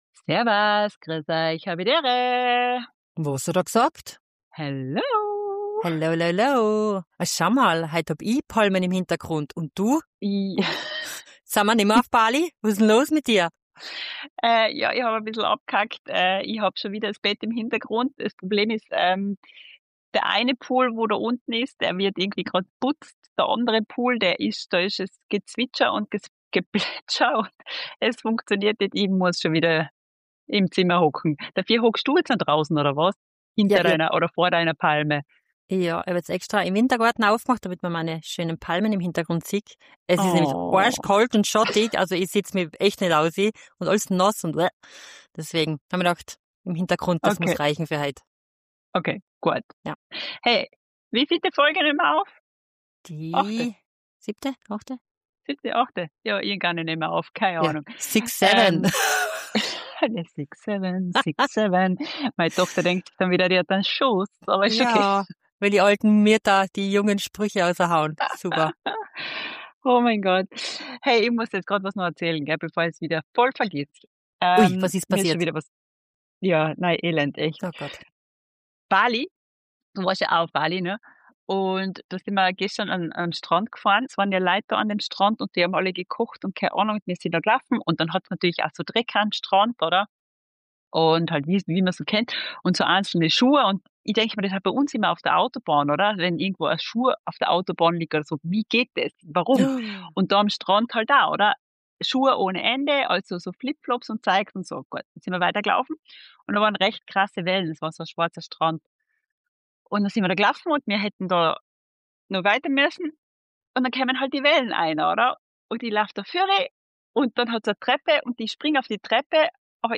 2 Schwestern aus Österreich seit über 20 Jahren in der Schweiz.